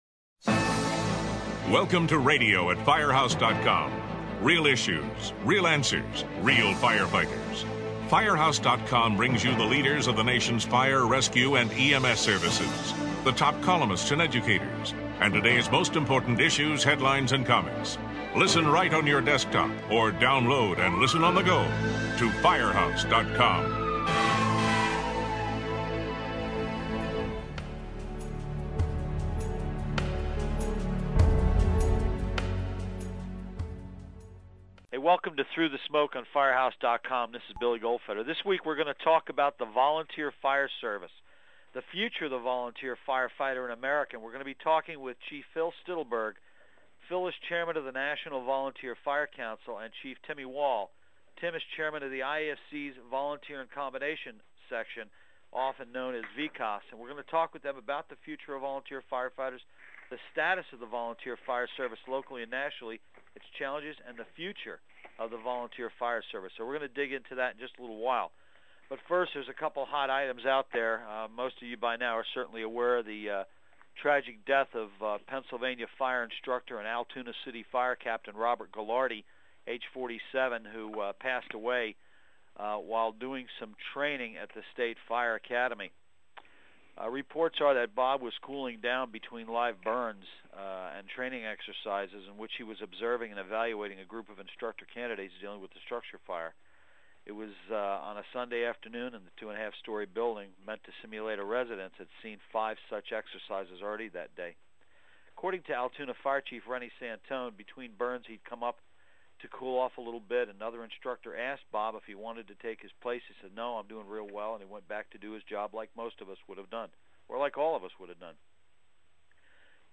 radio program